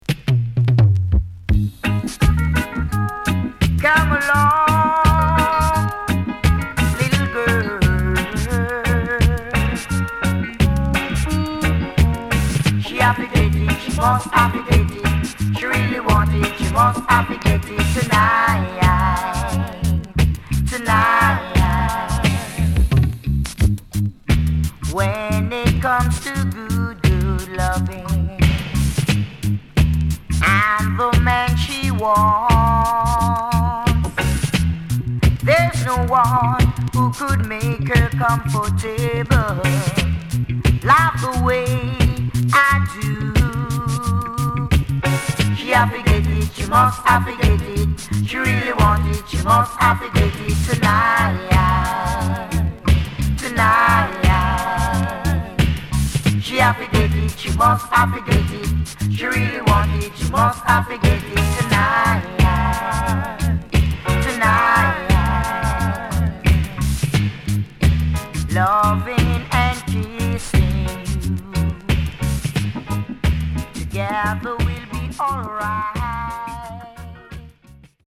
HOME > Back Order [DANCEHALL LP]  >  EARLY 80’s
80's Killer Vocal
SIDE A:少しチリノイズ、プチノイズ入ります。